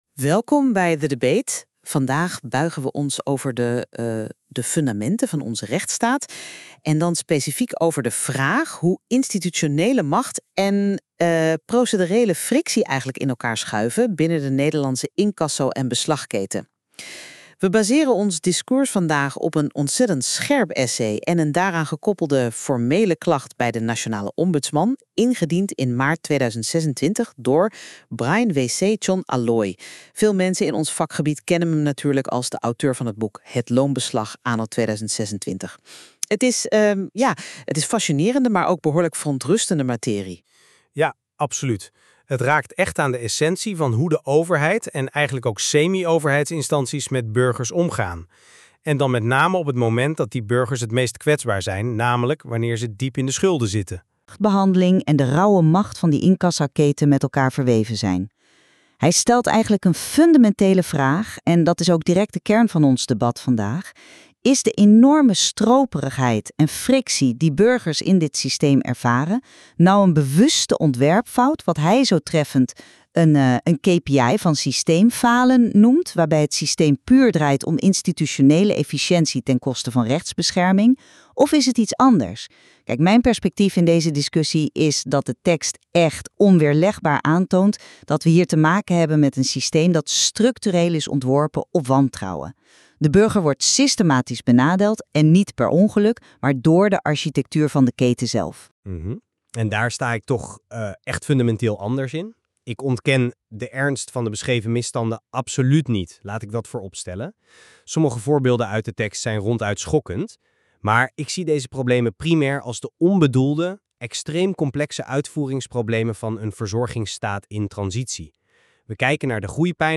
Deze AI-gegenereerde podcast gaat over dit artikel. De hosts bespreken de inhoud, geven context en voegen een interpretatie en mening toe.